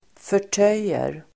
Uttal: [för_t'öj:er]